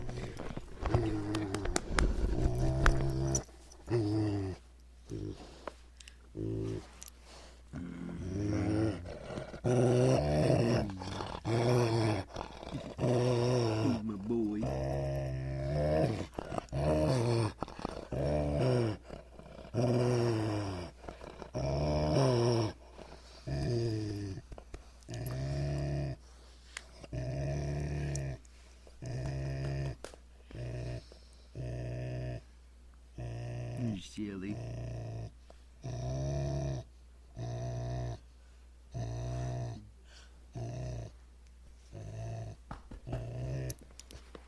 rottweiler_happy_growl.mp3